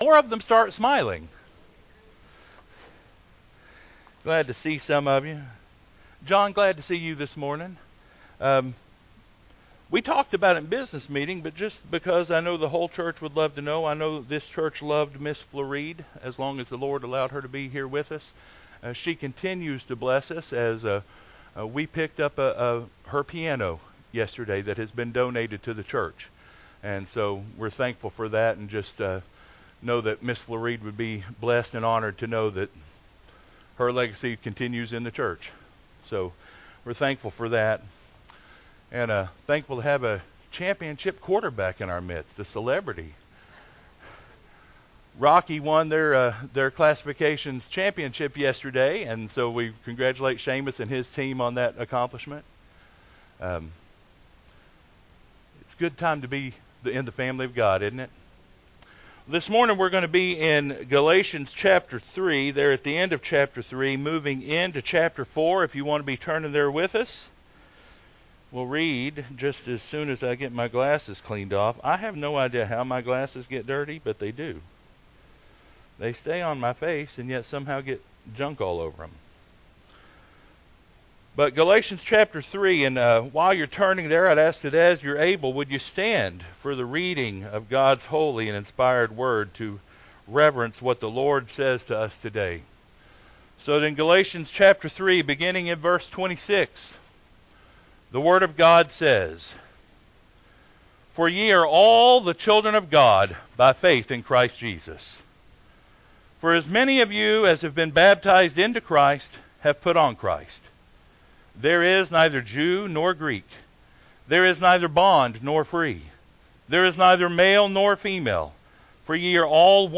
Children of God – AM Service